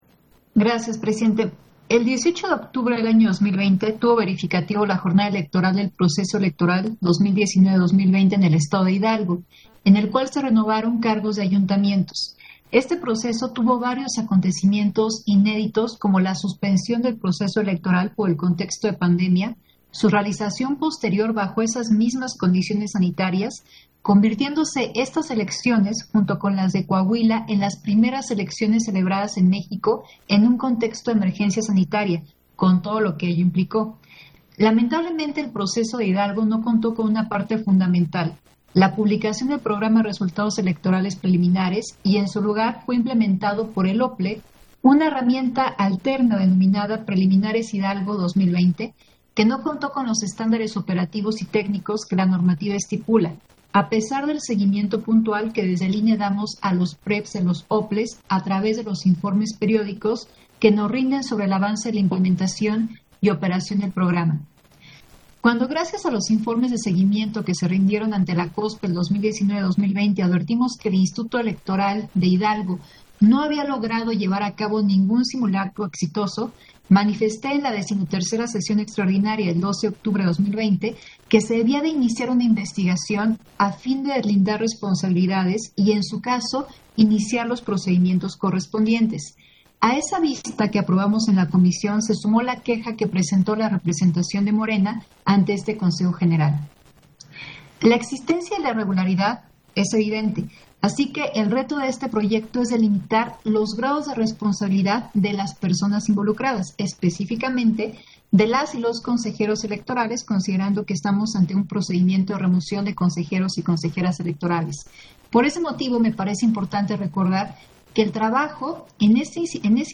Intervención de Dania Ravel, en Sesión Extraordinaria, relativo a la remoción de Consejeras y Consejeros Electorales del IEE de Hidalgo